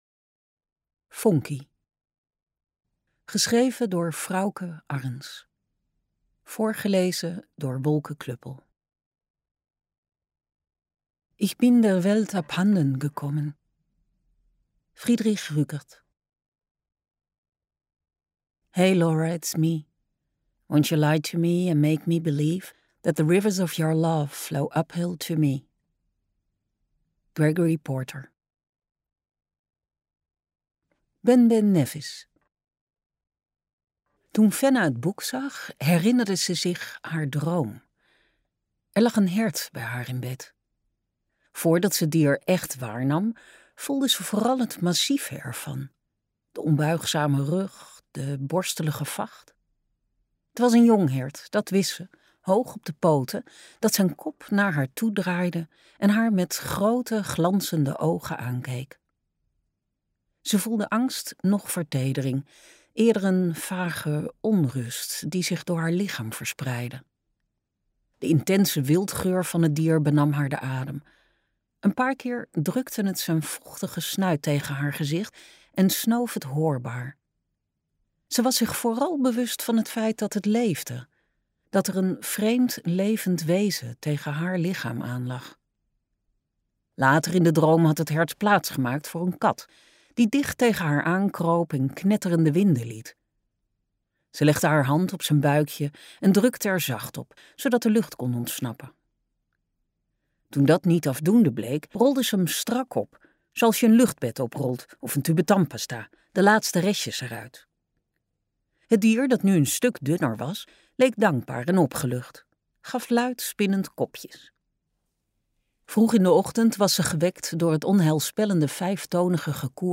Ambo|Anthos uitgevers - Vonkie luisterboek